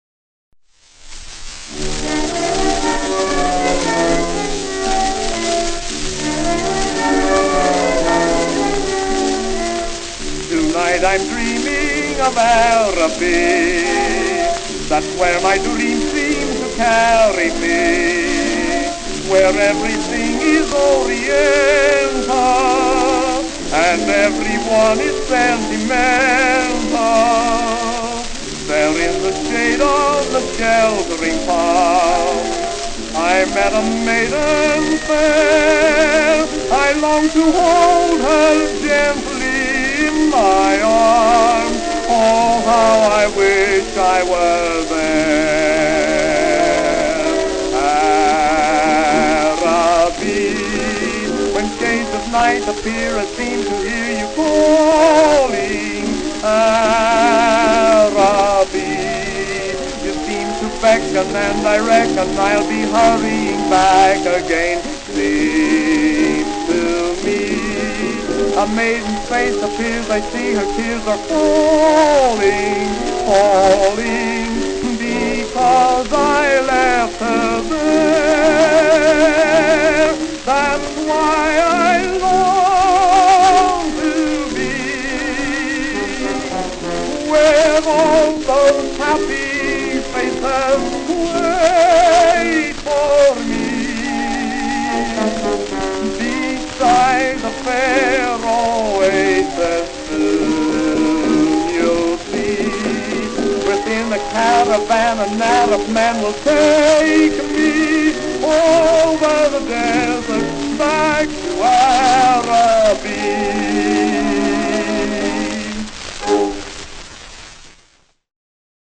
Tenor Solo